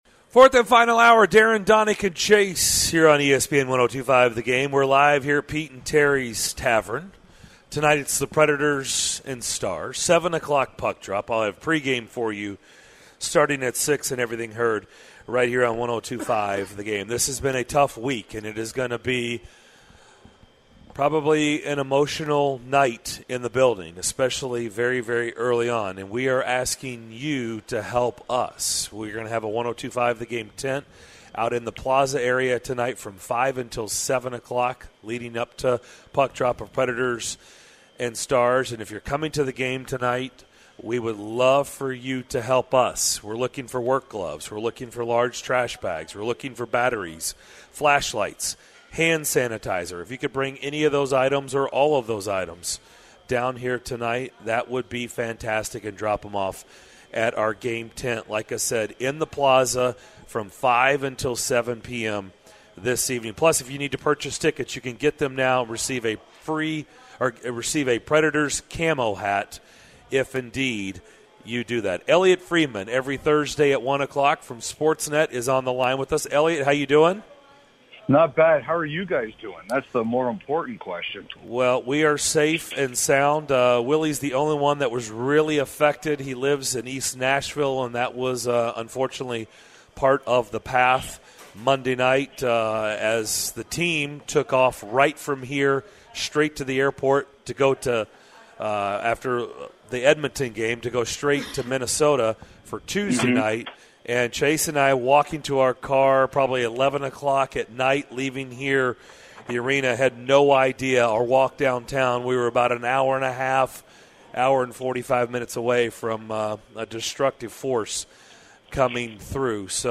Elliotte Friedman with Sportsnet joins the show to talk about the Preds and the GM meeting in Boca Raton, Florida.